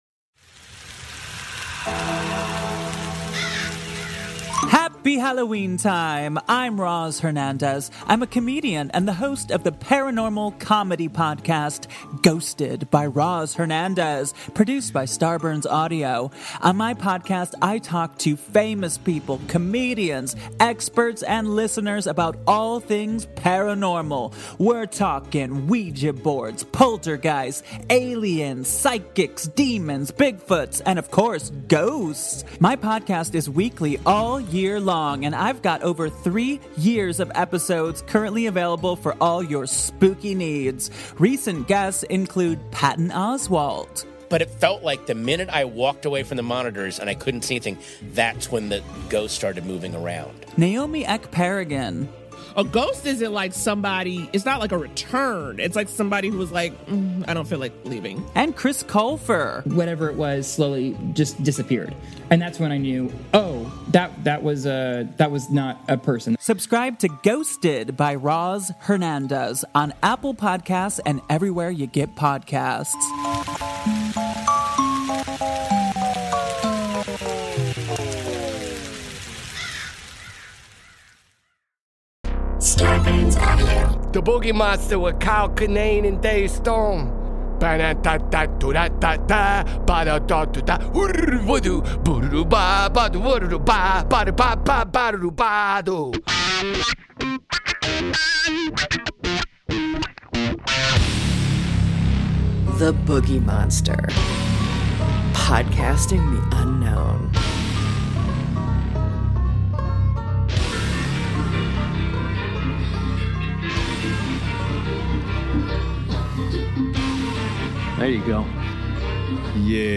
The guys are back in the record room for a tequila-fueled discussion on a variety of topics, including overrated avocados, airport drinking, hitchhikers, oysters, pre-Halloween season, and depression.